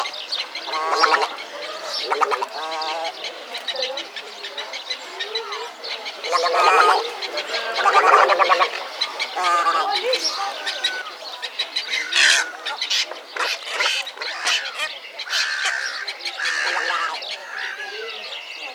LITTLE EGRET
Little-egret.mp3